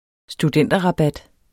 Udtale [ sduˈdεnˀdʌ- ]